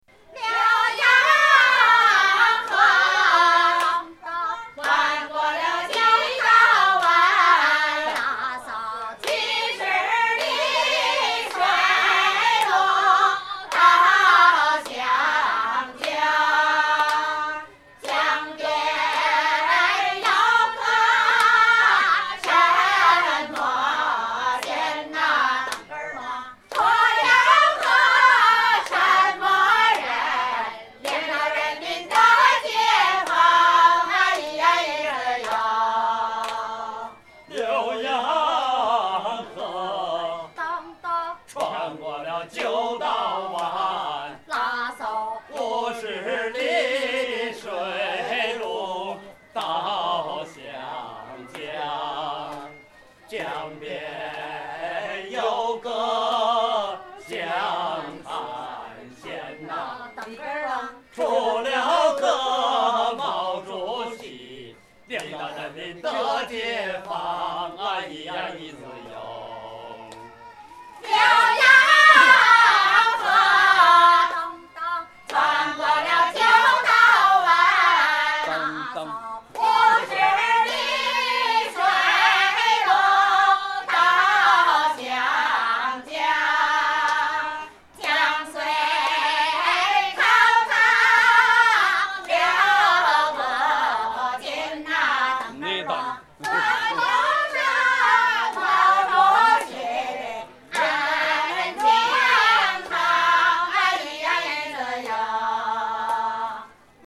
beijing choir